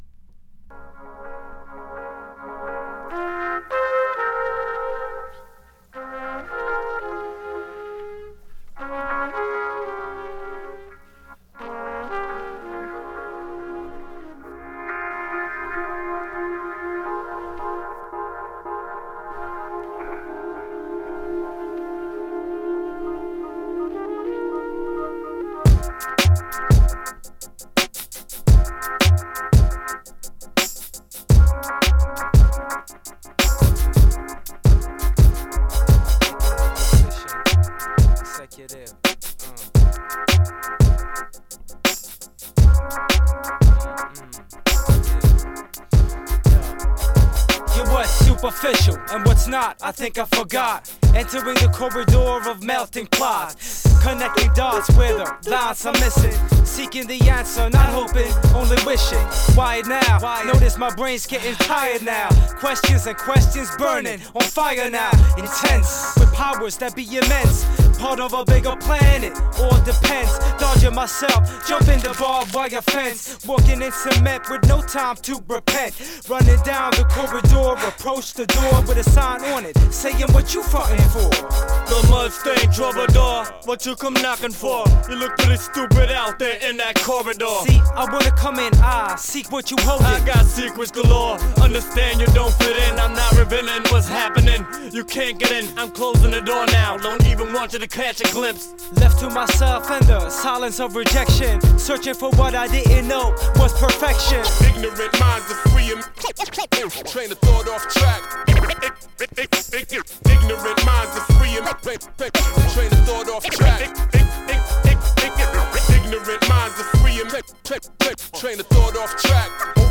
un trompettiste jazz très intéressant
mais remixé